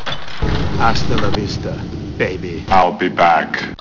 Tremendously Evil growls on the breaks during the show.